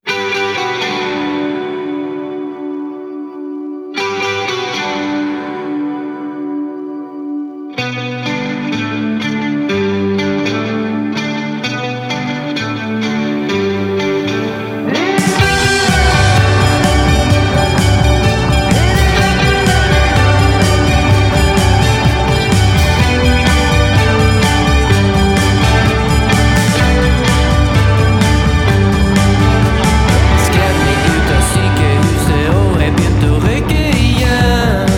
# Инди-рок